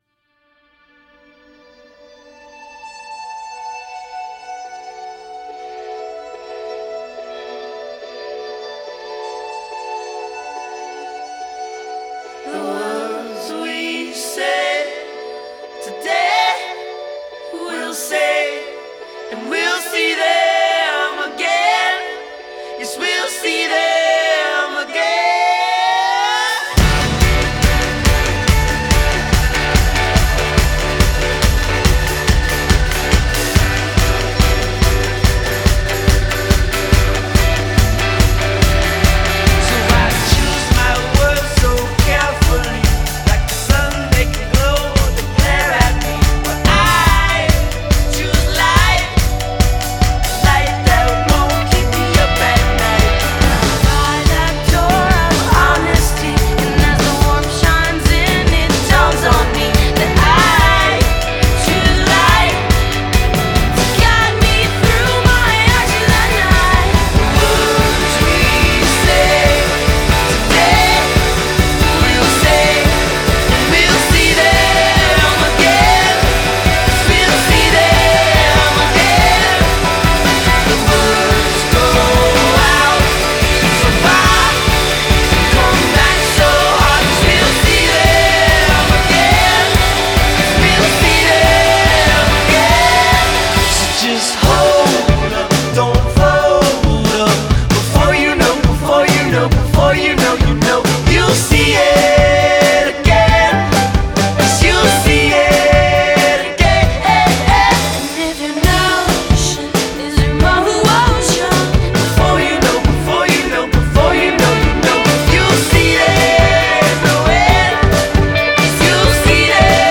Today’s Emo